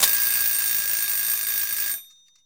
timer.ogg